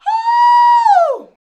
HUUUUUH.wav